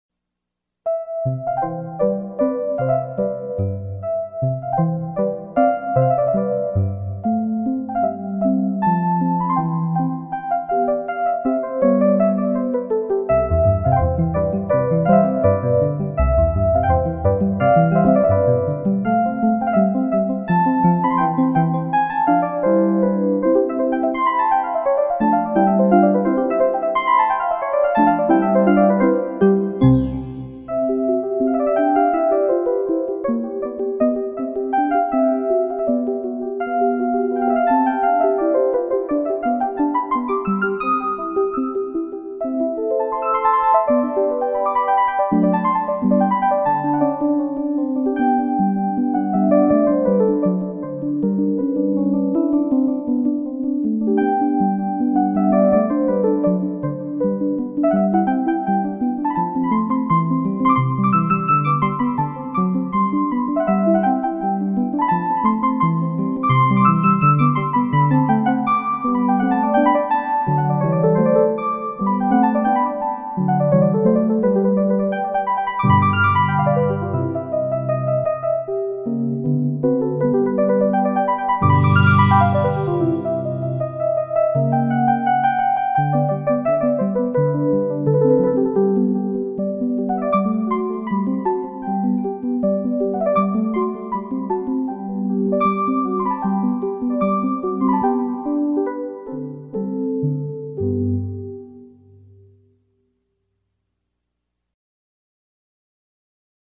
Соната для фортепиано до мажор - Моцарт - слушать
Классическая музыка величайшего композитора для взрослых и детей.
piano-sonata-in-c.mp3